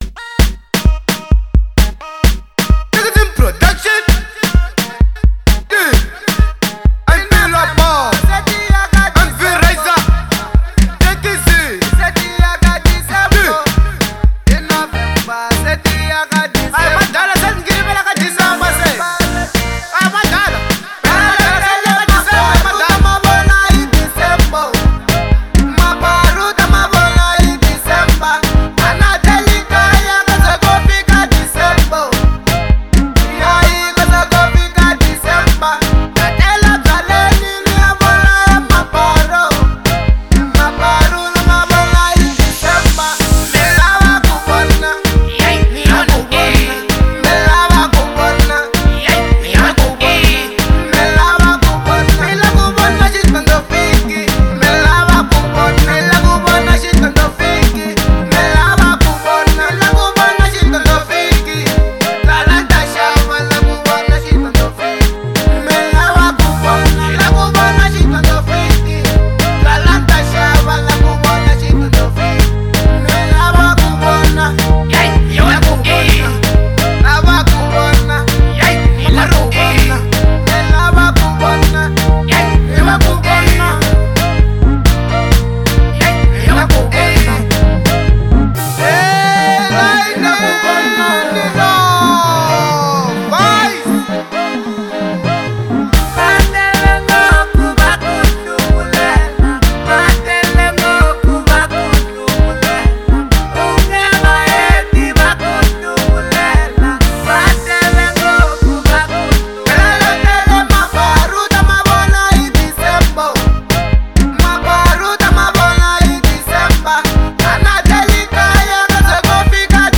Genre : Trap